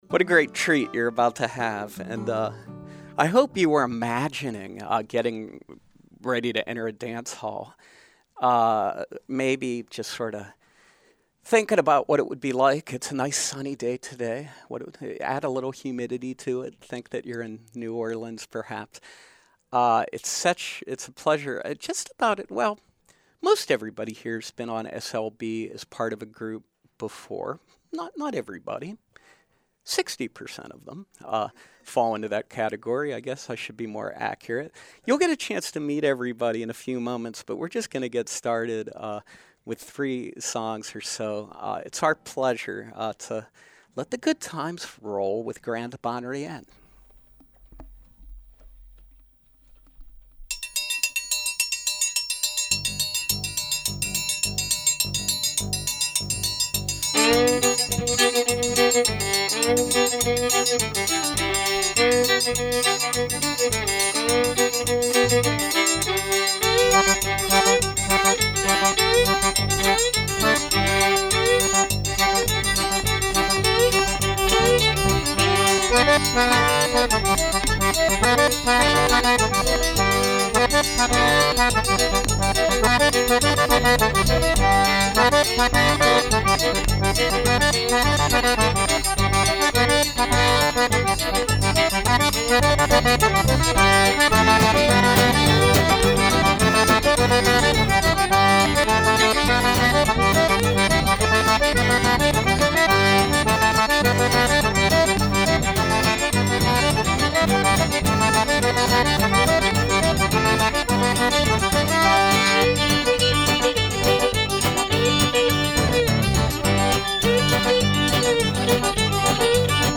live music featuring traditional Cajun Music